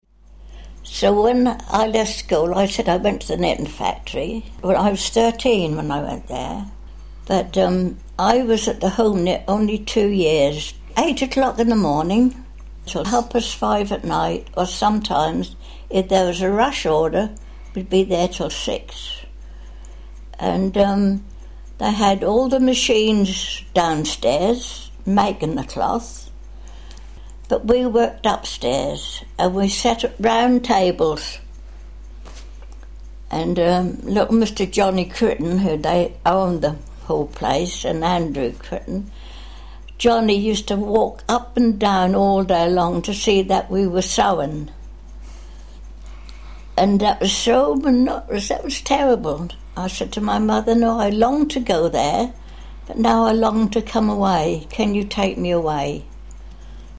Listen to the experiences of two former Homeknit employees.